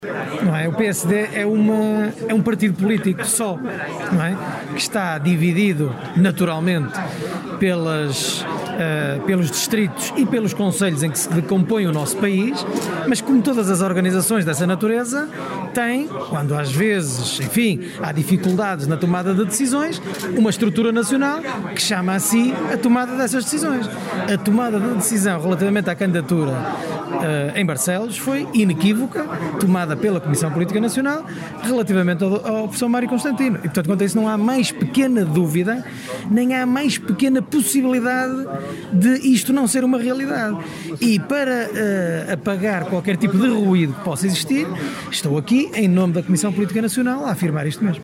Declarações de André Coelho Lima, dirigente nacional dos sociais-democratas, à margem da apresentação de mais 12 candidatos às juntas de freguesia pela Coligação Barcelos Mais Futuro, encabeçada por Mário Constantino.